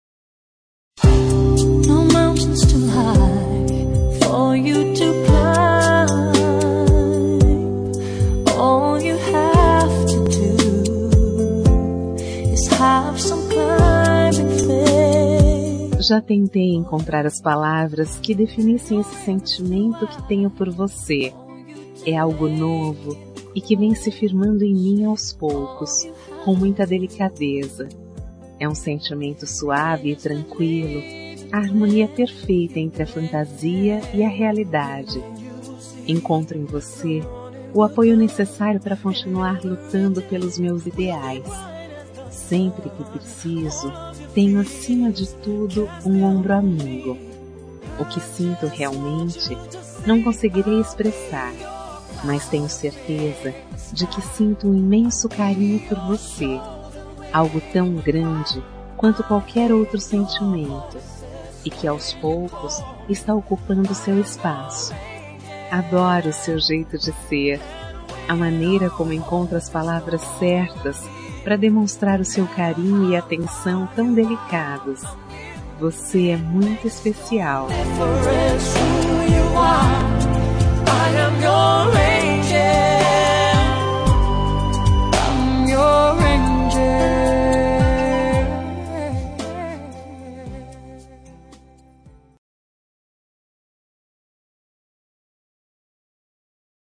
Telemensagem Você é Especial – Voz Feminina – Cód: 5416